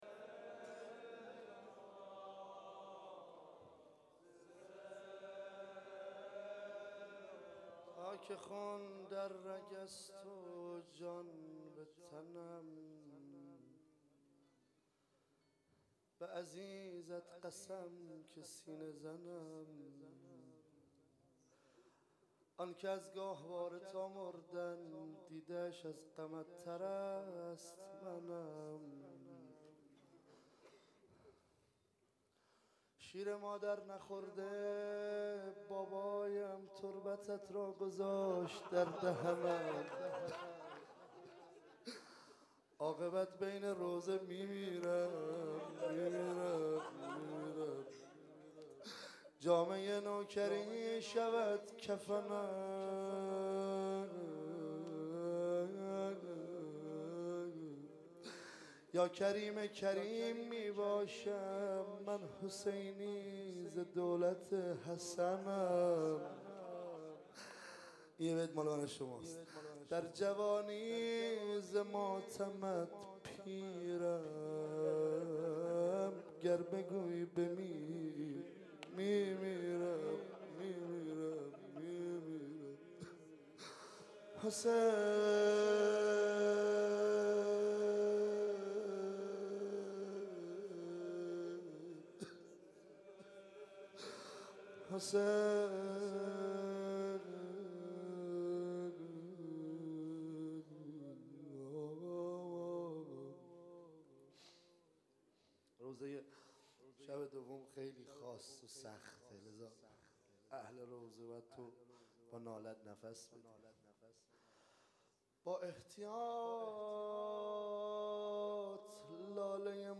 شب دوم محرم ۱۳۹۸ – ساری